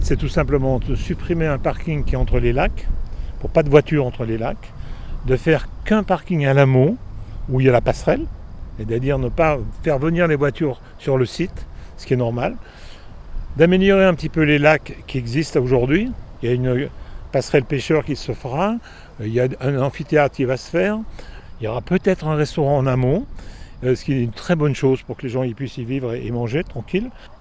Georges Morand, le maire de Sallanches, présente les futurs changements notables.